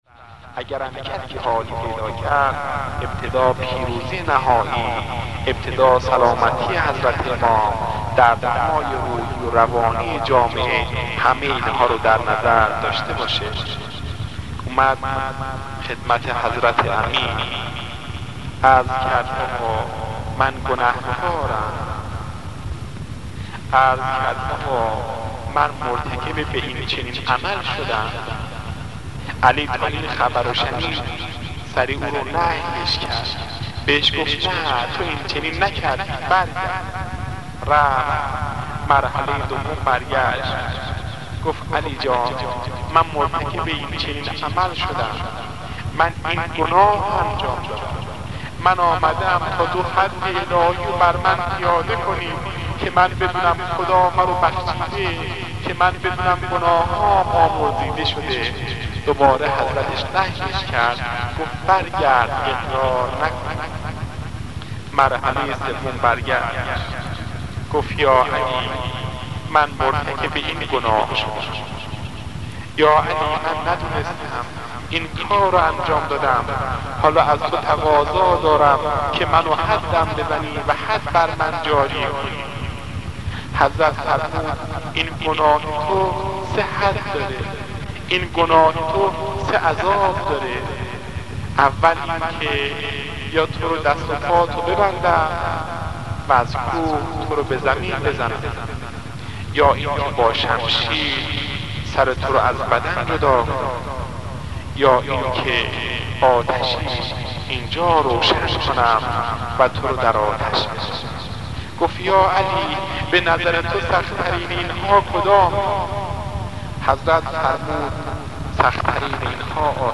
بخشی از سخنرانی